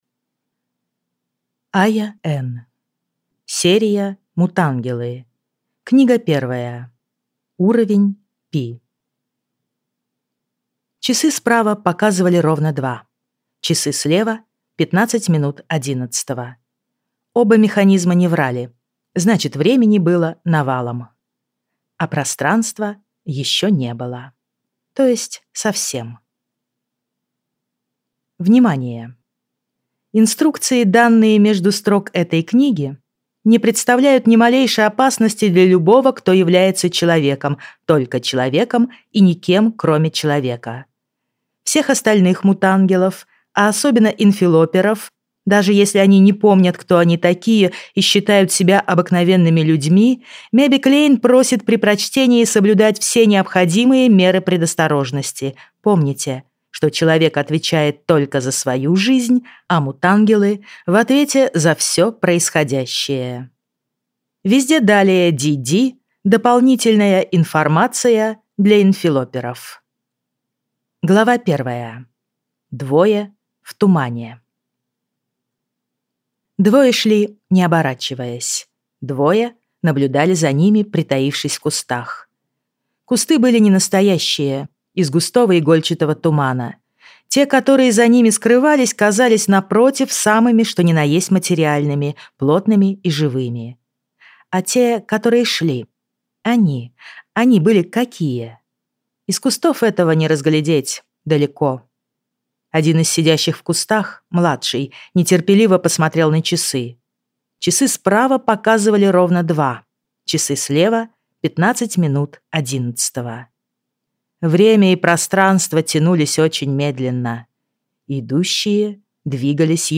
Аудиокнига Уровень Пи | Библиотека аудиокниг